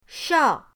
shao4.mp3